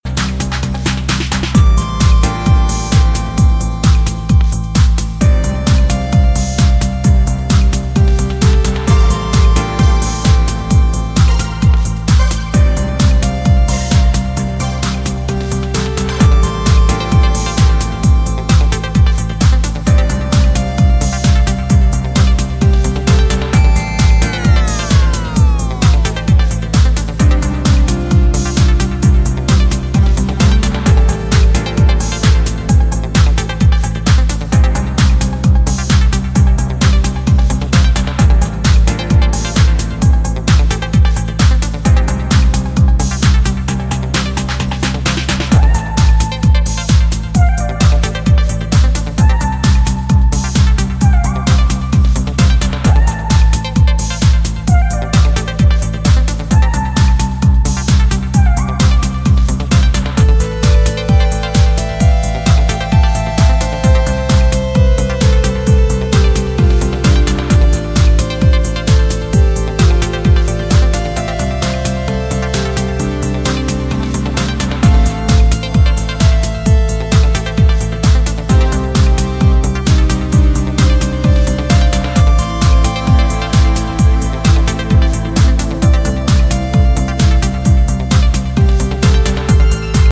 Classy cuts glittering with originality and outright funk.